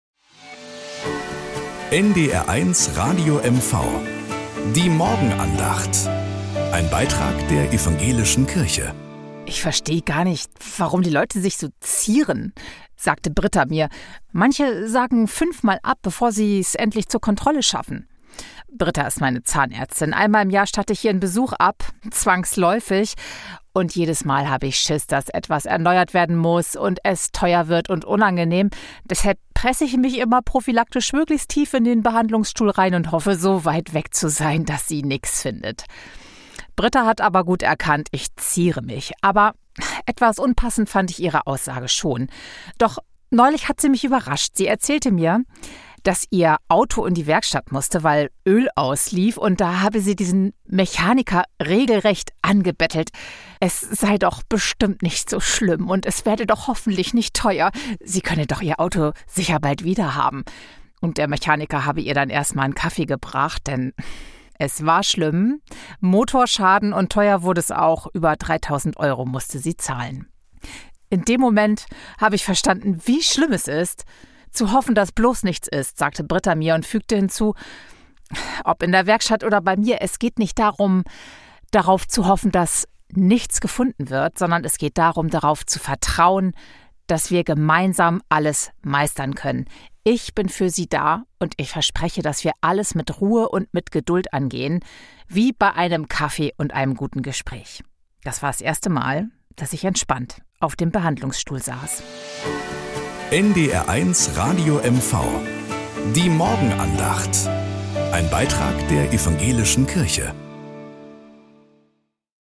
Nachrichten aus Mecklenburg-Vorpommern - 11.03.2025